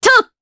Aselica-Vox_Jump_jp.wav